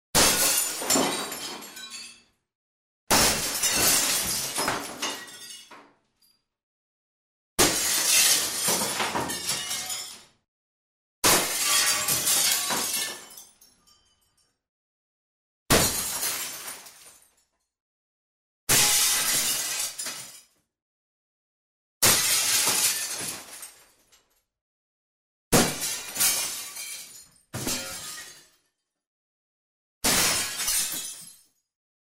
Звуки разбитого стекла
Звон разбитого оконного стекла